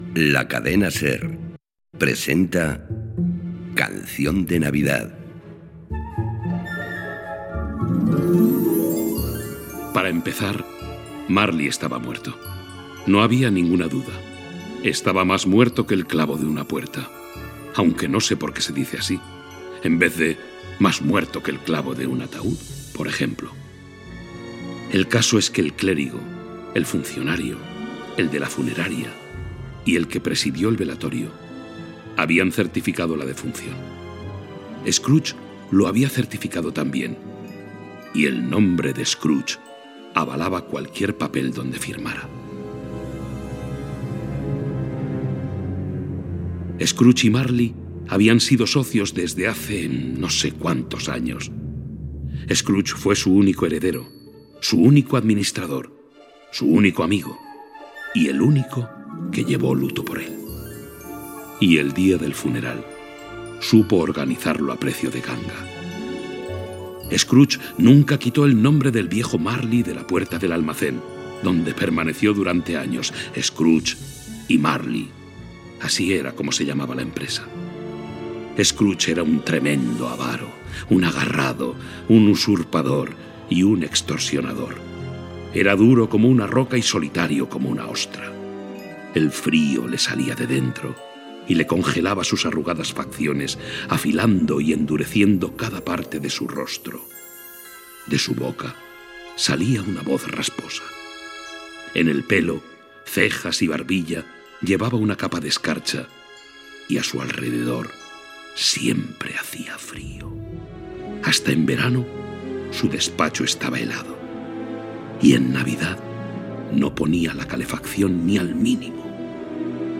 Versió radiofònica de "Canción de Navidad", de Charles Dickens.
Careta, el narrador situa l'acció i primeres escenes
Ficció
Protagonitzat per José María Pou, Juan Echanove, José Luis García Pérez y Carlos Hipólito, entre d'altres.